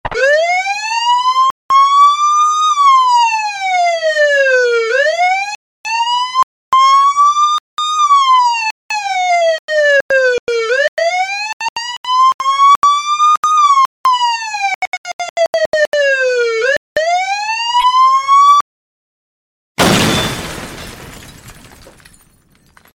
Error Police Car Sirene
car error police sound effect free sound royalty free Memes